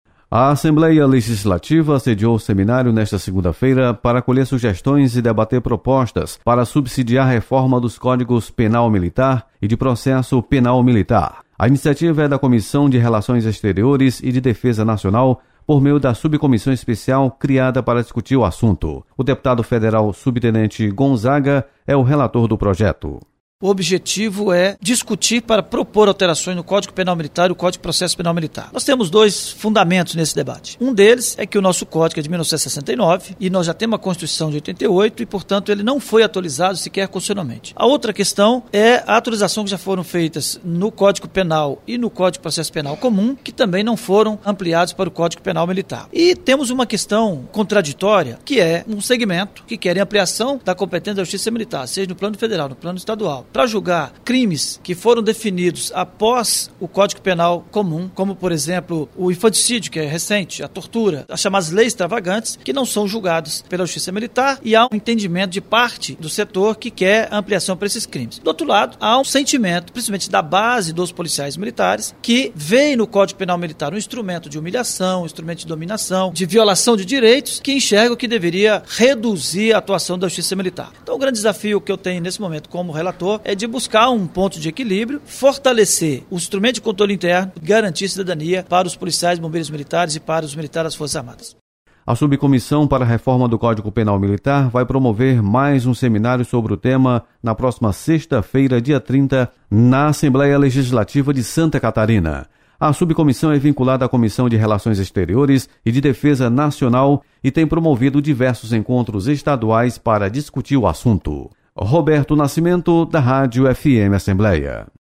Você está aqui: Início Comunicação Rádio FM Assembleia Notícias Seminário